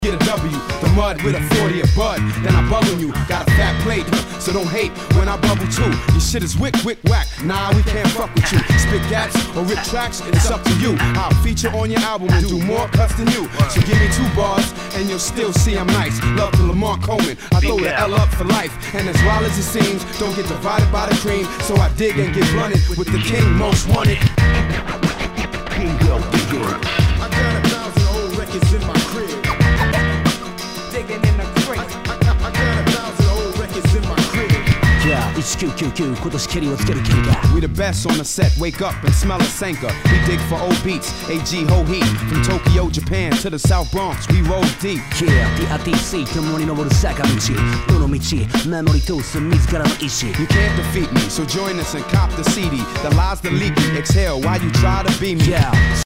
Jヒップホップ！！
HIPHOP/R&B